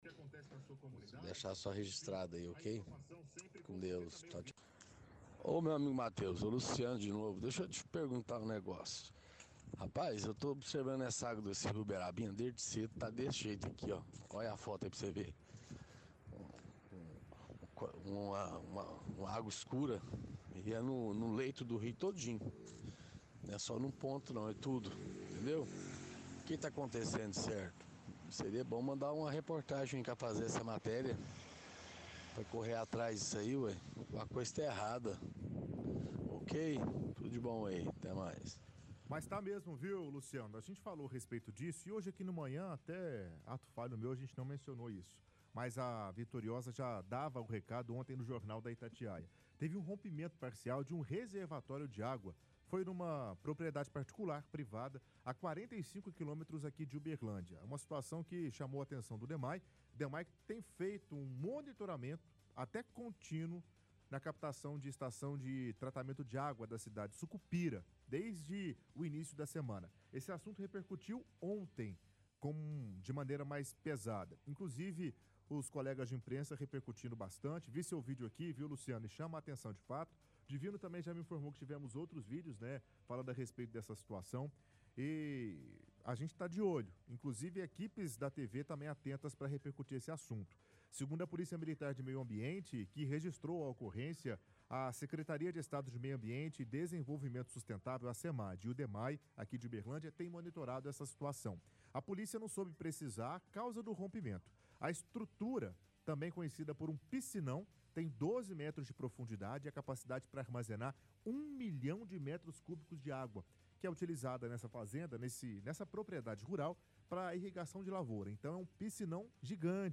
– Ouvinte comenta que água do Rio Uberabinha está escura.
– Apresentador explica o que aconteceu; lê matéria do G1